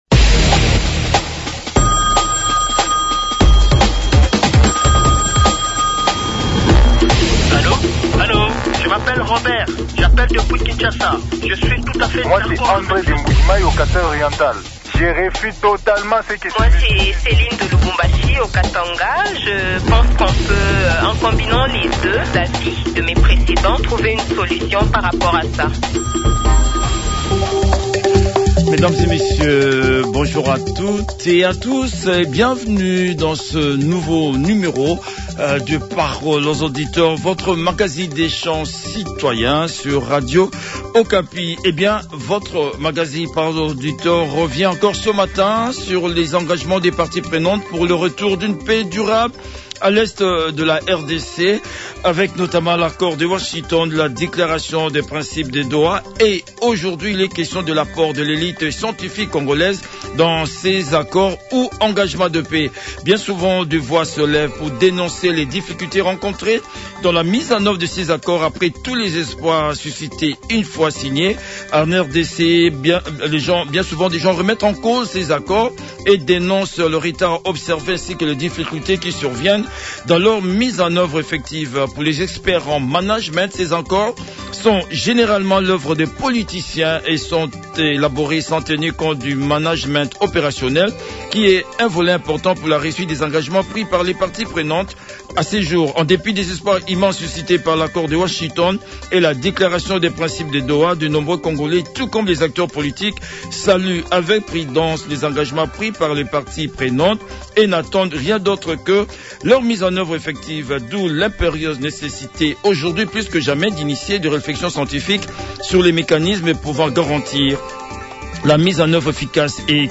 Les auditeurs ont échangé avec